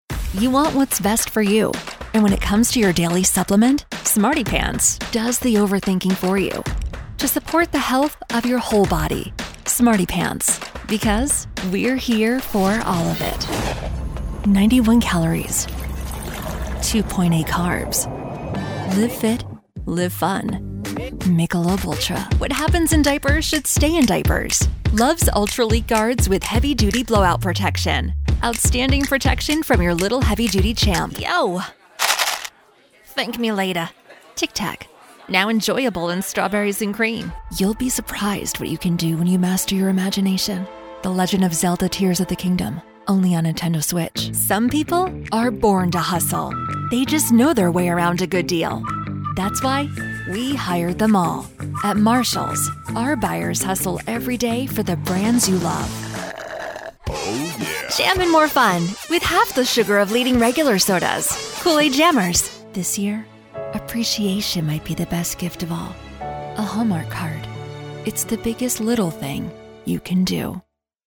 Male 20s , 30s , 40s , 50s
Assured , Authoritative , Character , Confident , Engaging , Friendly , Gravitas , Natural , Reassuring , Smooth , Warm , Versatile